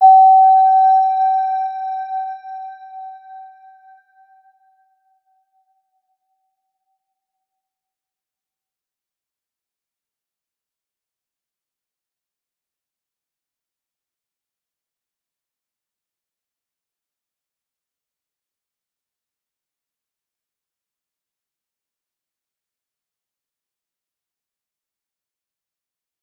Round-Bell-G5-f.wav